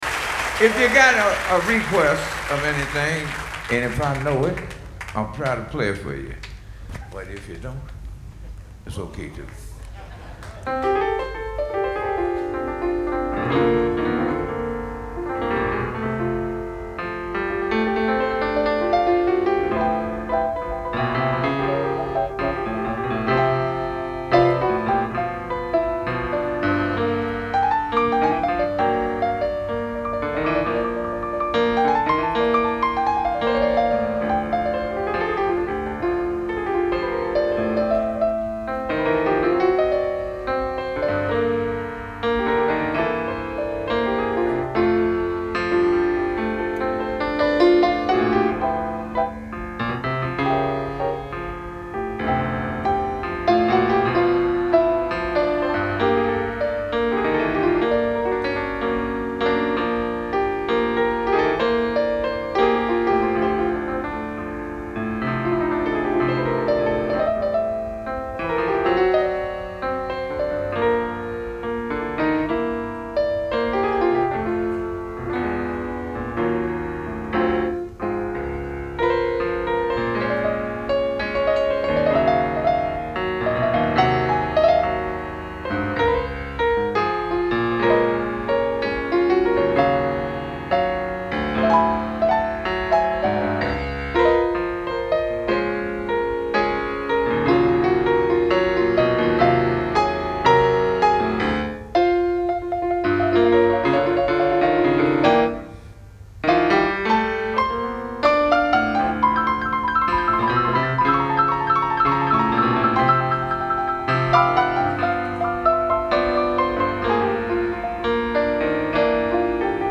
Genre : Jazz
Medley